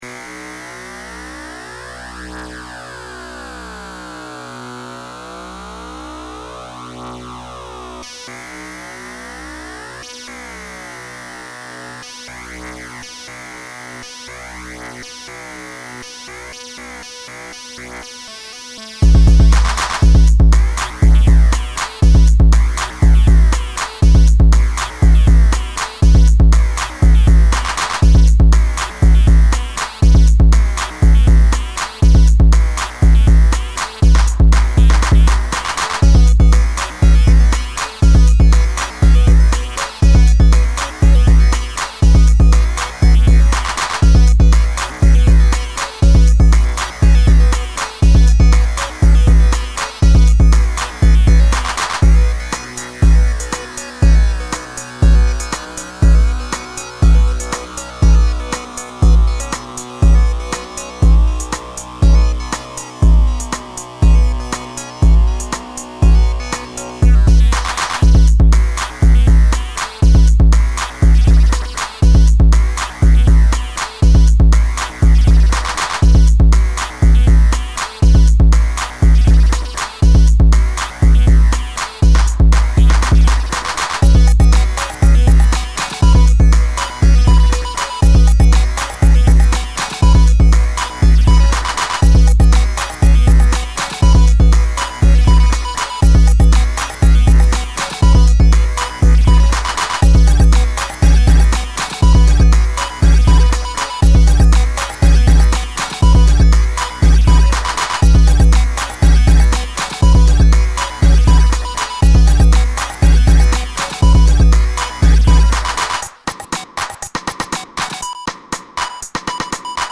a track recently which is in this ballpark.
(Kind of a big MP3, sorry about that. All compression efforts made the sound get muddy and gross.)